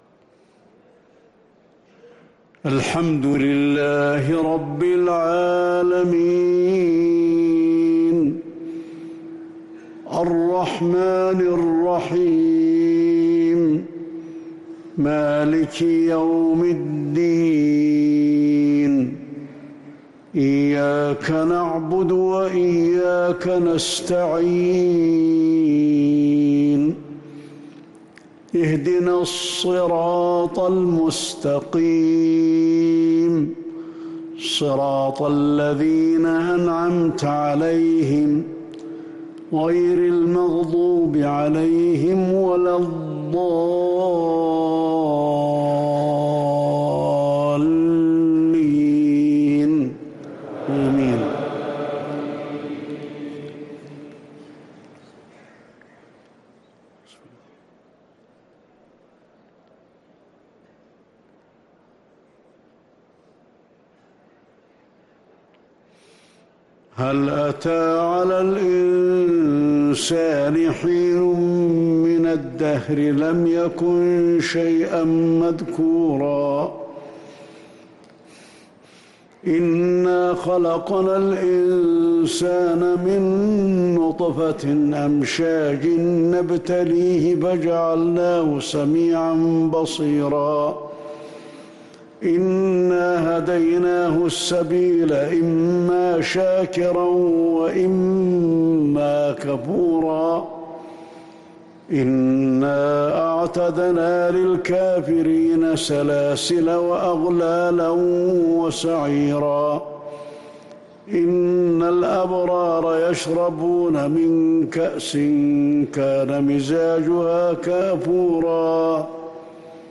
فجر الثلاثاء 4 محرم 1444هـ سورة الإنسان | Fajr prayer from Surah Al-Insan 2-8-2022 > 1444 🕌 > الفروض - تلاوات الحرمين